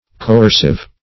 coercive - definition of coercive - synonyms, pronunciation, spelling from Free Dictionary
Coercive \Co*er"cive\, a.